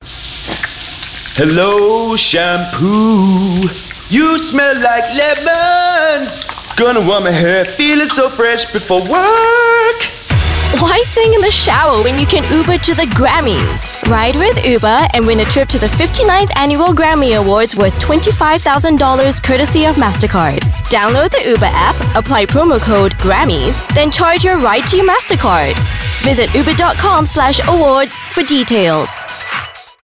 Voice Samples: Uber to the Grammys
EN Asian
female